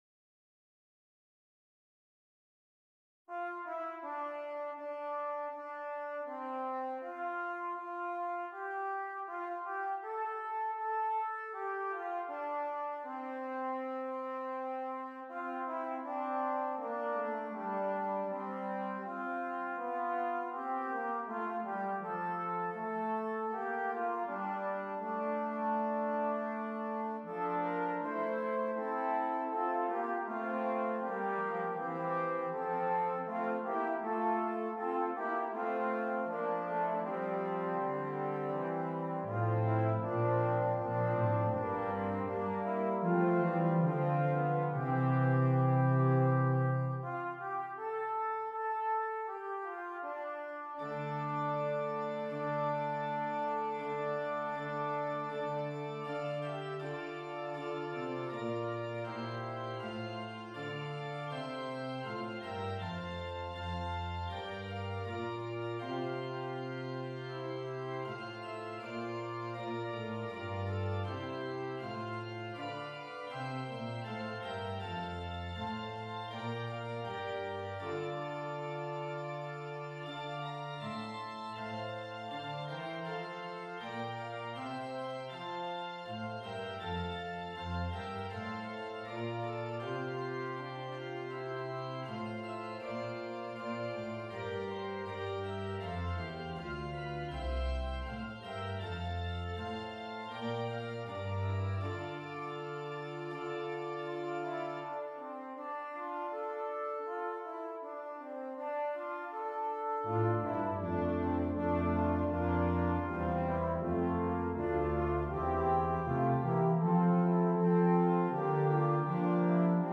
Brass Quintet and Organ
Traditional English Folk Song